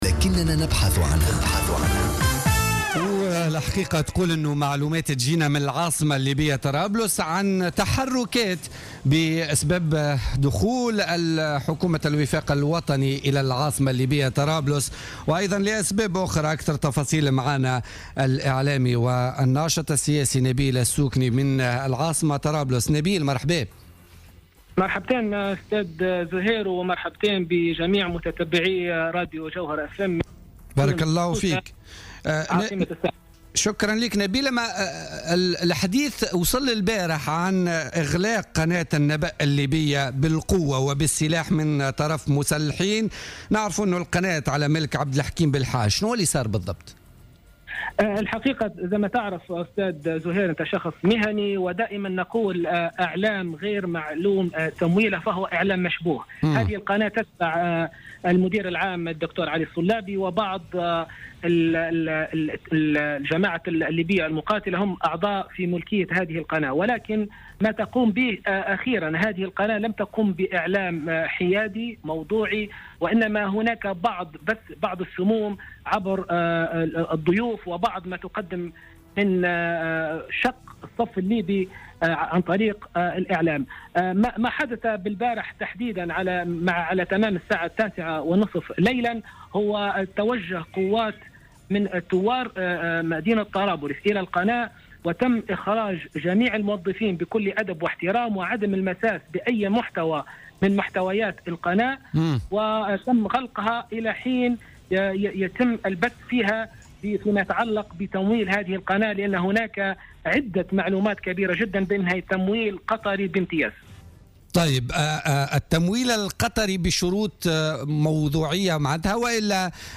في مداخلة له في بوليتيكا اليوم